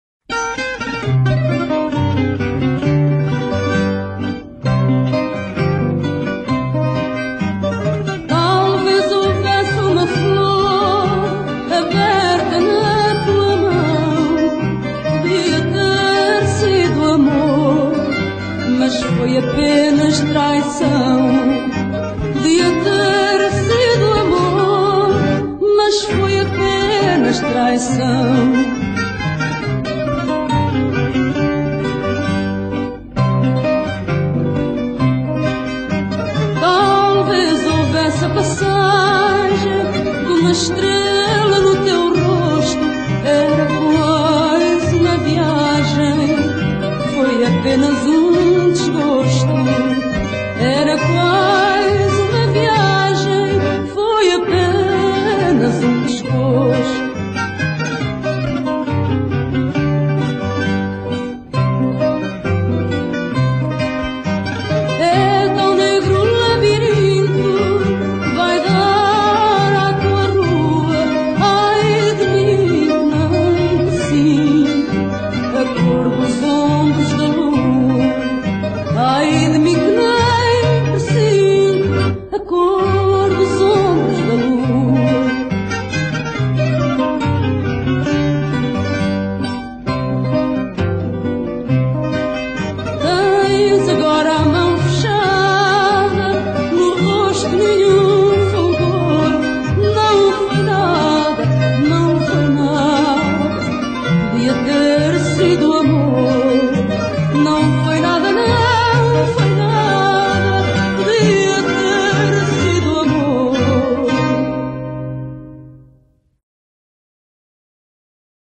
chant
guitare portugaise
guitare classique
basse acoustique.
from → Fado castiço / Fado traditionnel